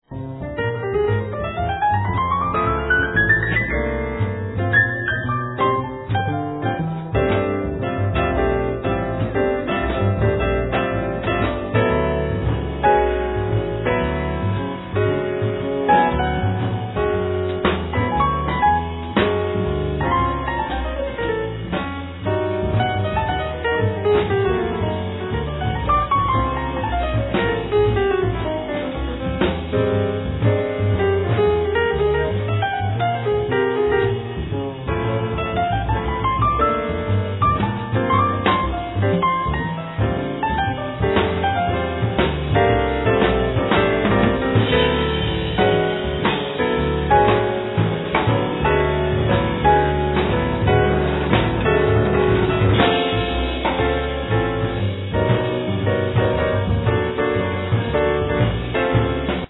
Piano
Drums
Double bass
Saxophone